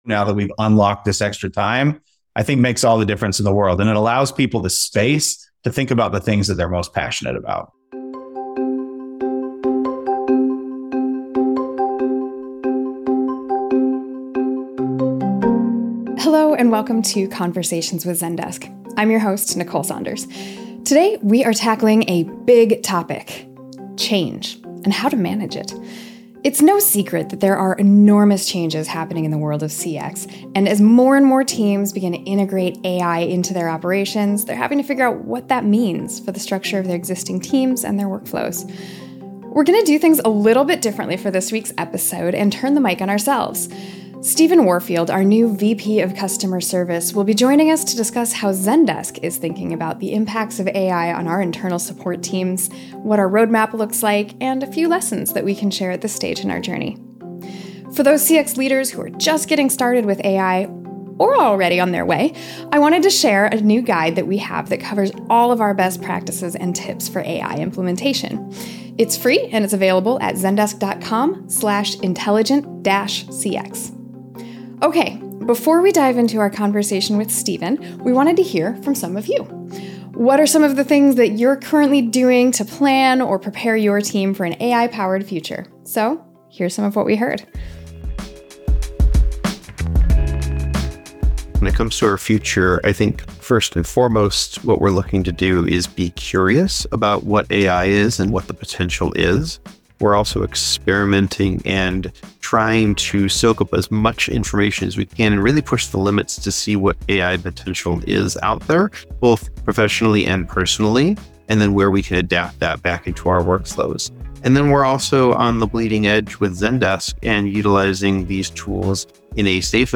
Conversations with Zendesk - Interviews about Customer Service, Support, and Customer Experience